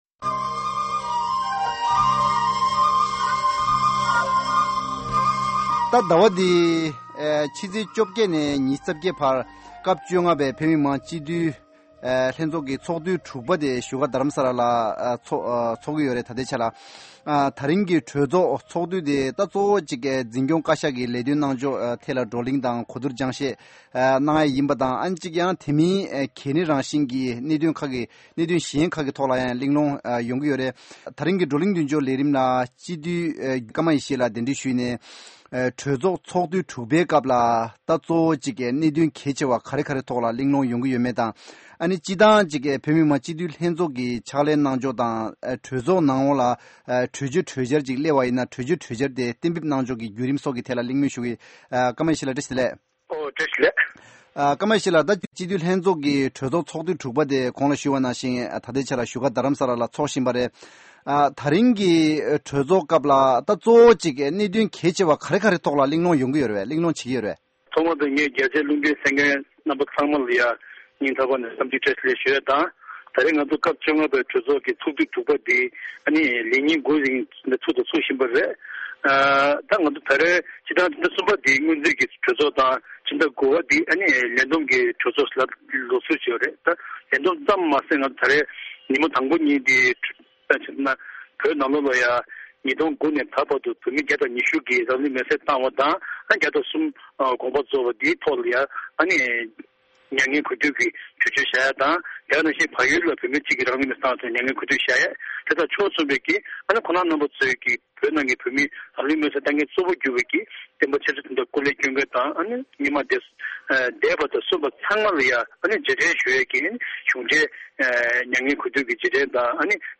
གཟའ་འཁོར་འདིའི་བགྲོ་གླིང་མདུན་ལྕོག་ལས་རིམ་ལ་སྤྱི་འཐུས་སྐར་མ་ཡི་ཤེས་ལགས་གདན་འདྲེན་་ཞུས་ཏེ་སྤྱི་འཐུས་ལྷན་ཚོགས་ཀྱི་ཕྱག་ལས་གནང་ཕྱོགས་དང་ལས་དོན་བྱེད་སྒོའི་ཐད་གླེང་མོལ་ཞུ་གི་་ རེད།